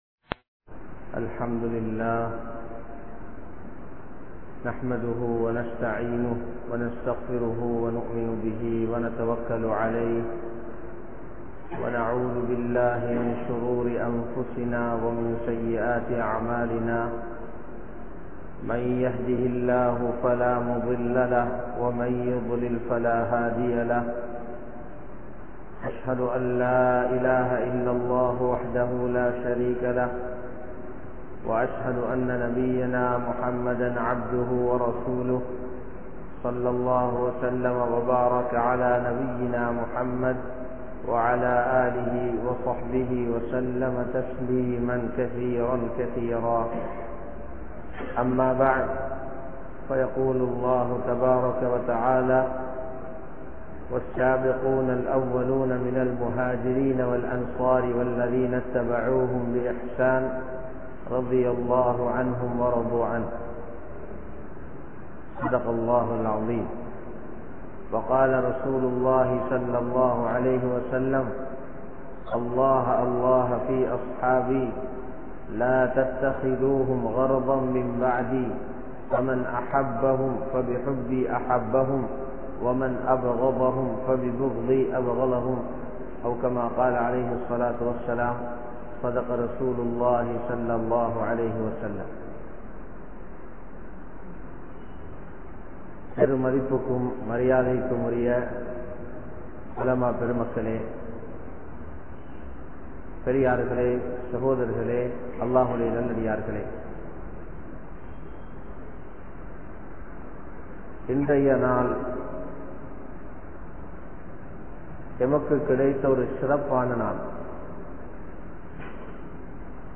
Sahabaakkalin Sirappu (ஸஹாபாக்களின் சிறப்பு) | Audio Bayans | All Ceylon Muslim Youth Community | Addalaichenai